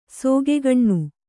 ♪ sōgegaṇṇu